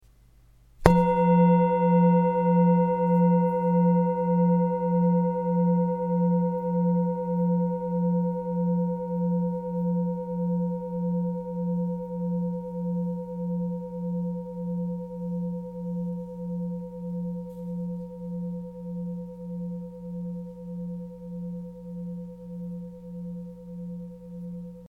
Klangschale Alte Universalschale 1116g KM80-326
ALTE TIBETISCHE KLANGSCHALE - UNIVERSALSCHALE
Grundton: 177,55 Hz
1. Oberton: 495,16 Hz